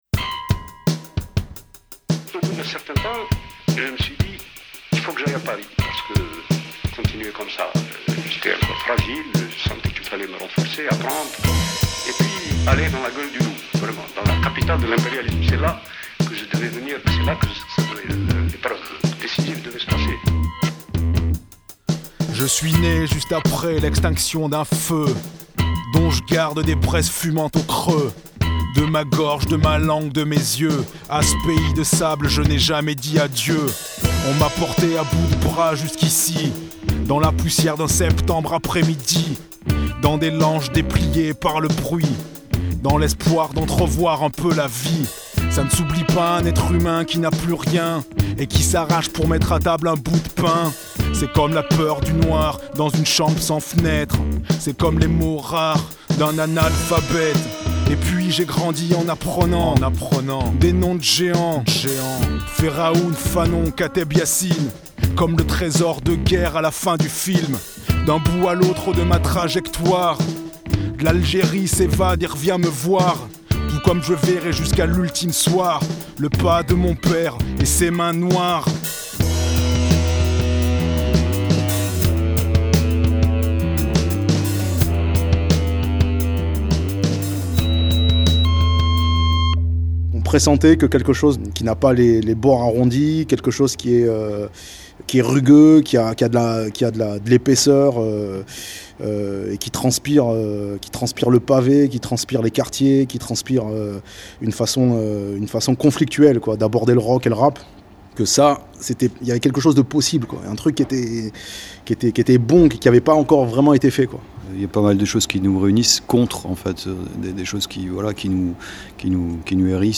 L'interview de Serge Teyssot-Gay, Hamé et Casey pour le disque de Zone Libre Vs Casey et Hamé, intitulé L'angle mort.